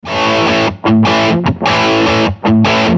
Rock-Guitar.MP3